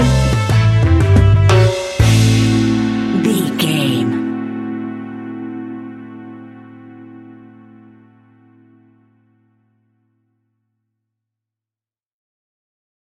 Classic reggae music with that skank bounce reggae feeling.
Aeolian/Minor
laid back
off beat
drums
skank guitar
hammond organ
percussion
horns